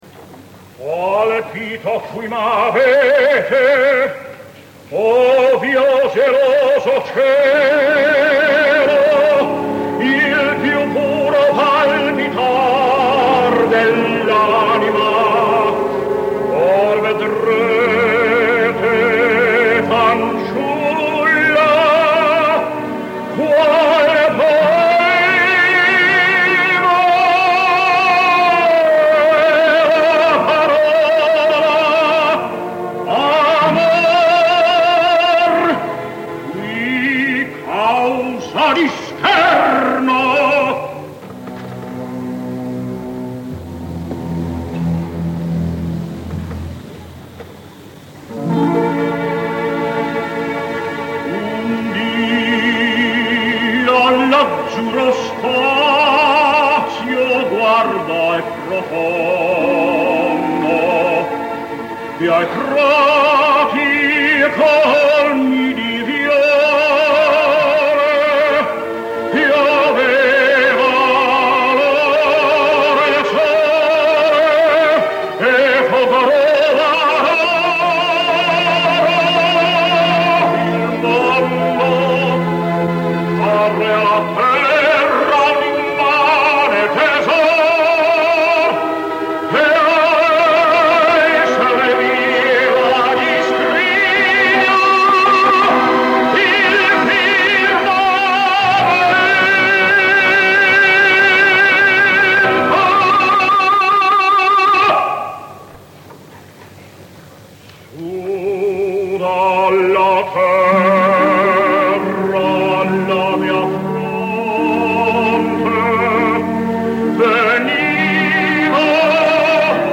El so no es precisament digital, tot i que digne, però el valor dels àudios que avui escoltarem ben bé valen una certa condescendència tècnica.
Dos anys més tard, Richard Tucker va tornar per cantar una òpera que li anava com anell al dit, Andrea Chénier. La tendència a emfatitzar els sentiments, tan comuna en el període del verisme musical, li servia a Tucker per enfervorir els auditoris i teatres i si no ho acabeu d’entendre escolteu aquest “Colpito qui m’avete…Un dì all’azzurro spazio”, exagerat,  amb frases o resolucions estètiques de dubtós gust, però d’eficàcia teatral inaudita. El Liceu, en acabar, s’ensorra.